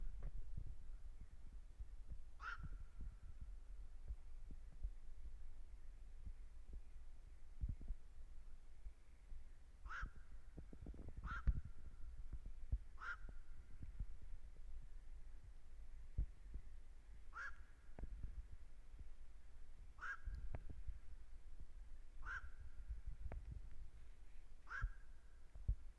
Nakts gārnis, Nycticorax nycticorax
StatussDzirdēta balss, saucieni
PiezīmesPielodoja no Z-Rietumiem un aizlidoja ezera virzienā. Bija dzirdams 1-2 minūtes.